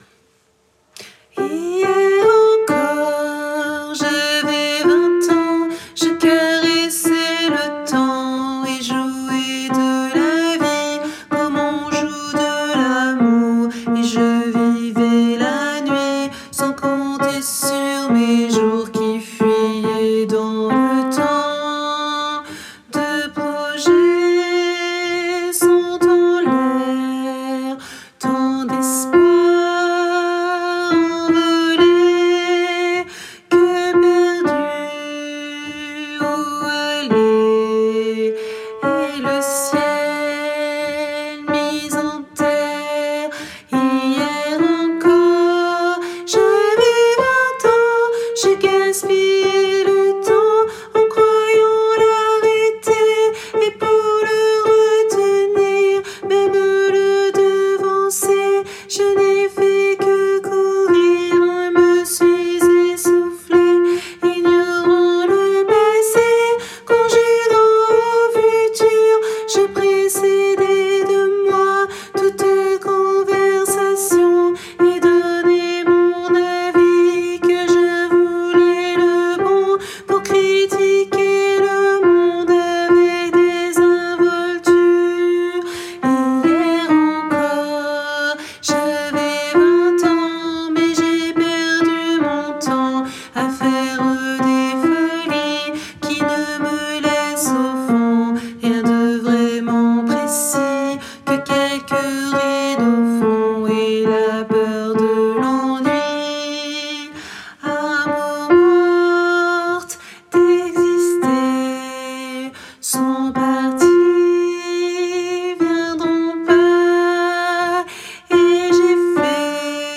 - Chant pour chœur mixte à 3 voix (SAH)
MP3 versions chantées
Alto